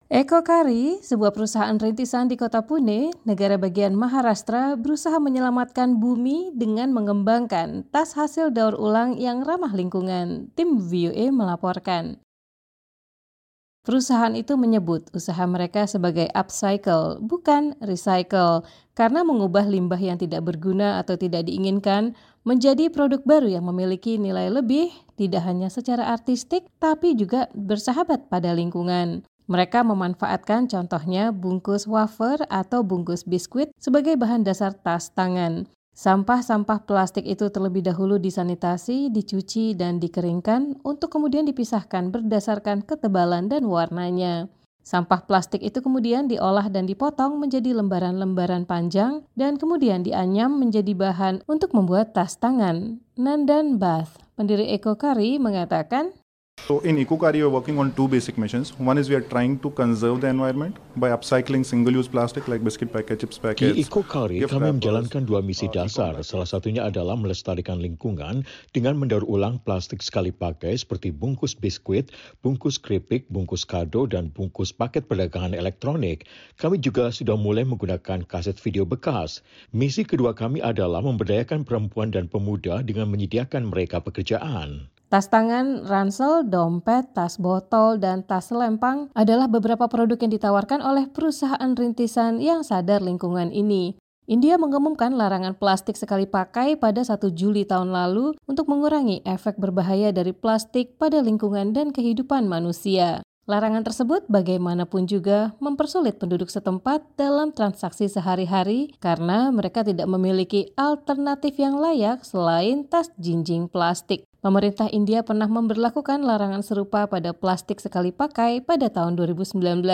EcoKaari, sebuah perusahaan rintisan di kota Pune, negara bagian Maharastra, berusaha menyelamatkan bumi dengan mengembangkan tas hasil daur ulang yang ramah lingkungan. Tim VOA melaporkan.